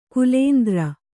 ♪ kulēndra